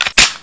assets/psp/nzportable/nzp/sounds/weapons/colt/slide.wav at 145f4da59132e10dabb747fa6c2e3042c62b68ff
PSP/CTR: Also make weapon and zombie sounds 8bit
slide.wav